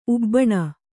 ♪ ubbaṇa